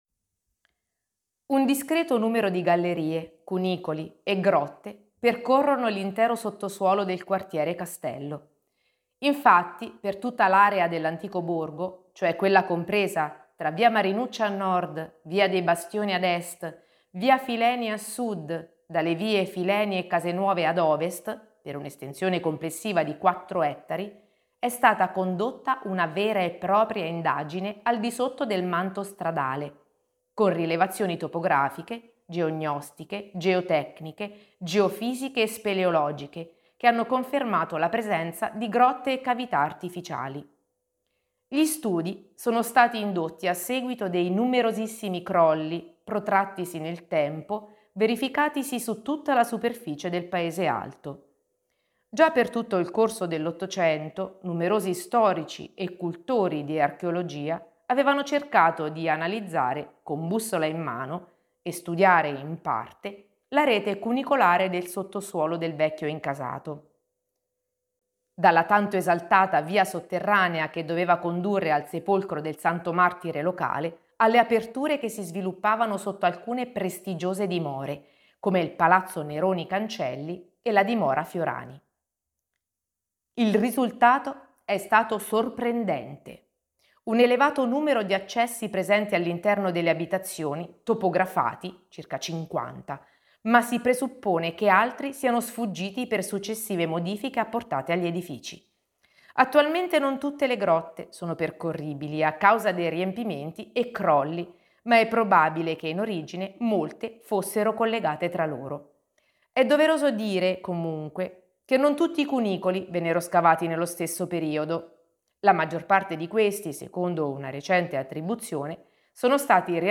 RIPRODUCI L'AUDIOGUIDA COMPLETA RIPRODUCI FERMA oppure LEGGI LA STORIA GUARDA IL VIDEO LIS